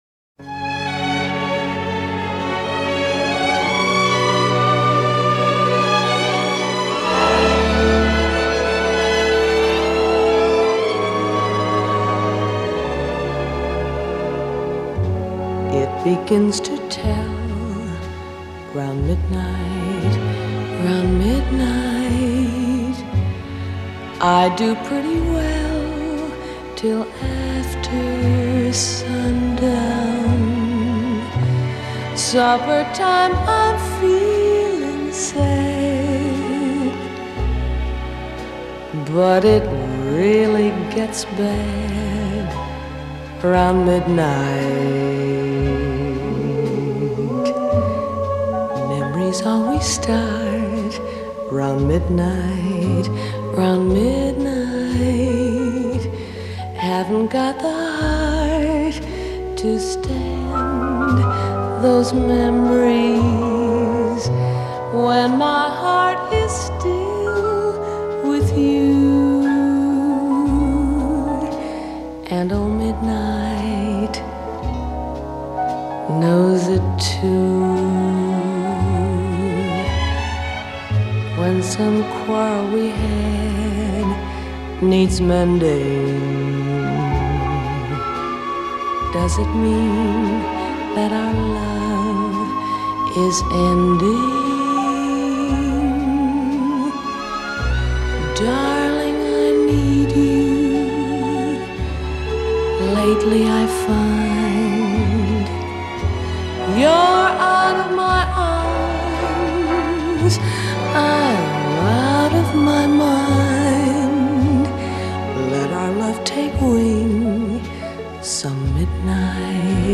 Actress-turned-singer
she’s backed by a full orchestra
a sweeping, romantic flair.